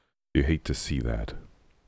Tags: Gen Z Deep Voice Yikes